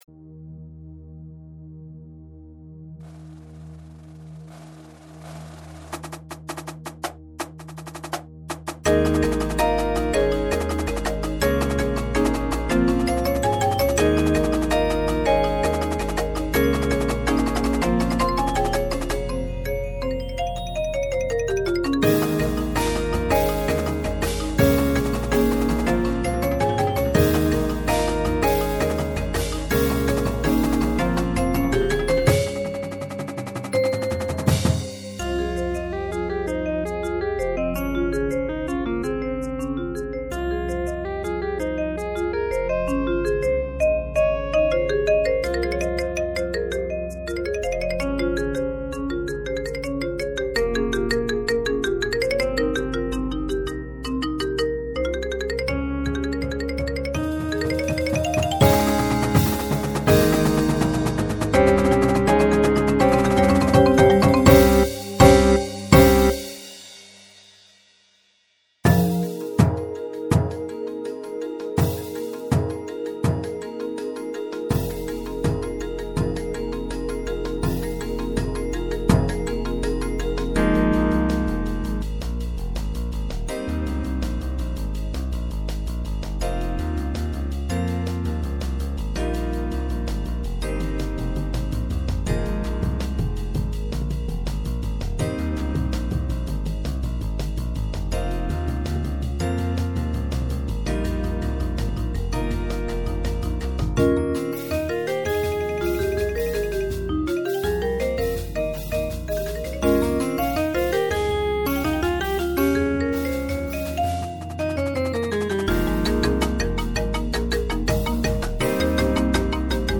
Bells
Xylophone
Marimba (3)
Vibes (2)
Synth (2)
Electric Guitar
Electric Bass
Auxiliary Percussion (2)
Snare
Tenors (quints)
Bass Drums (5)
Cymbals